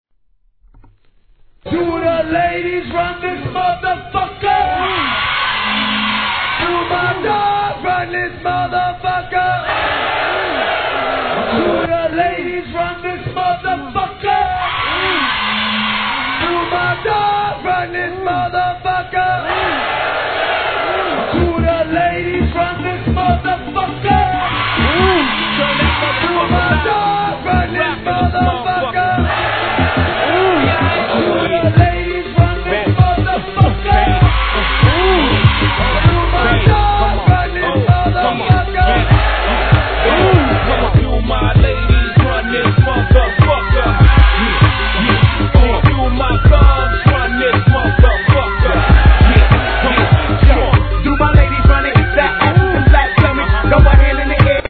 HIP HOP/R&B
(100 BPM)
(99 Bounce Version) (Club 98 BPM) No. タイトル アーティスト 試聴 1.